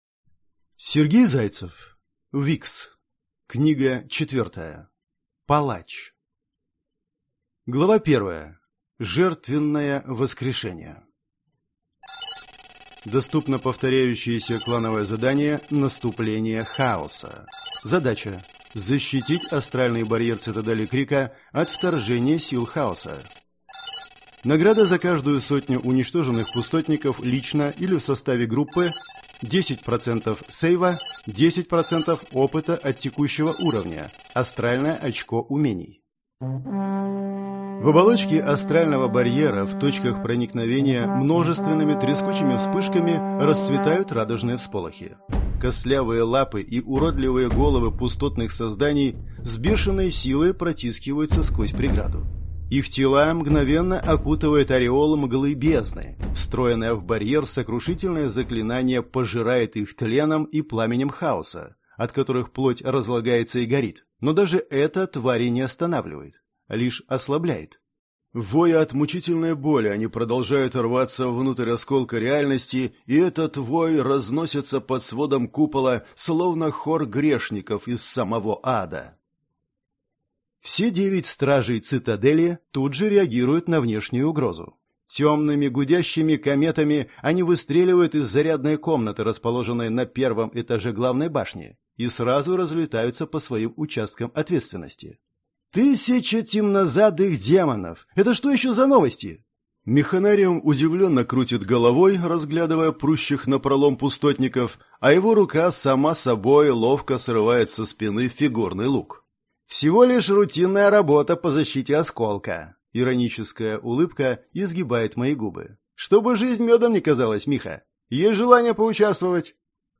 Аудиокнига ВИКС. Палач | Библиотека аудиокниг